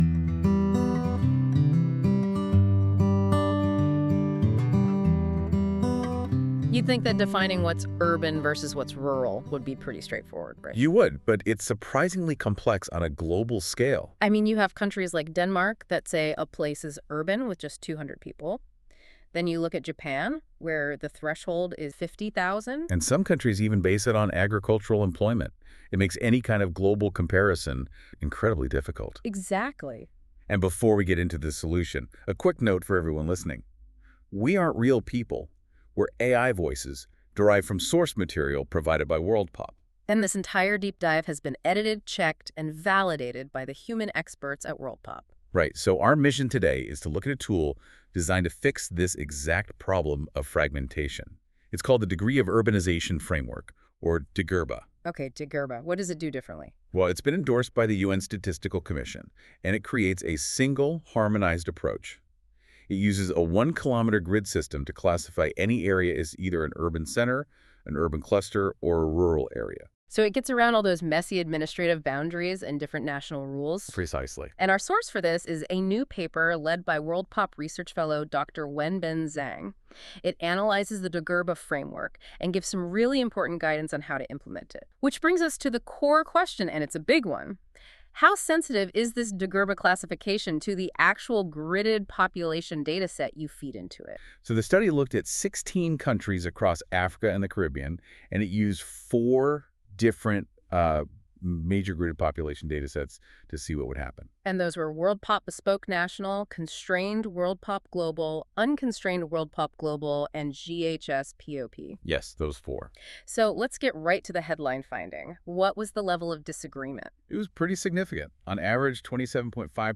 This feature uses AI to create a podcast-like audio conversation between two AI-derived hosts that summarise key points of documents - in this case the “Assessing the impacts of gridded population model choice on degree of urbanisation metrics” paper linked below.
Music: My Guitar, Lowtone Music, Free Music Archive (CC BY-NC-ND)